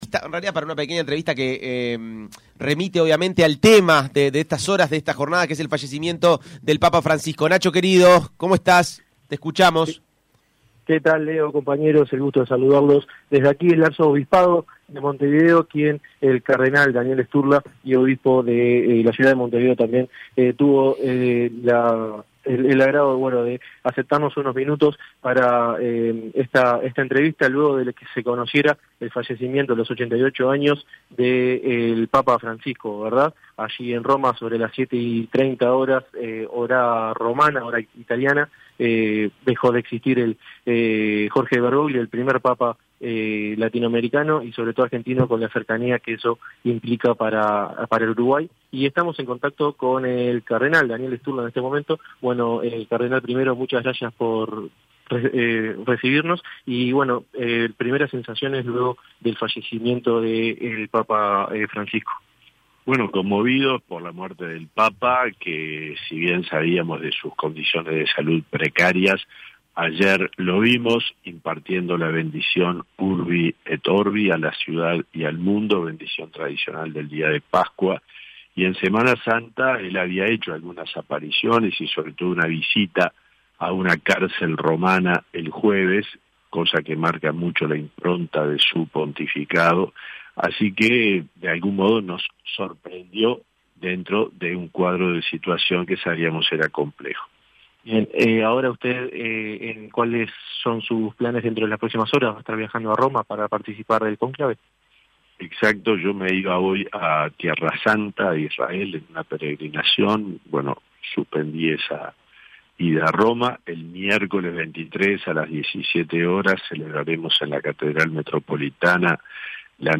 El Cardenal Daniel Sturla, en diálogo con Punto de Encuentro, confirmó que estará viajando hacia Roma el próximo jueves para participar del Cónclave, la reunión de cardenales de todo el mundo “menores a 80 años” para elegir al nuevo Papa.